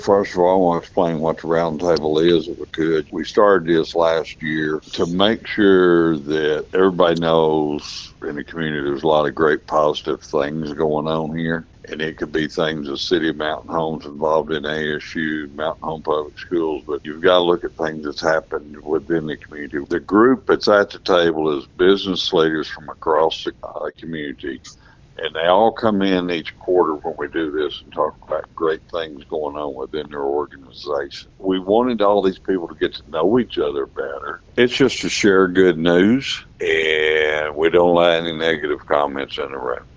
Following the event, Mayor Hillrey Adams shared key takeaways in an interview with KTLO, Classic Hits and The Boot News. To begin the conversation Mayor Adams clarifies the purpose of the roundtable.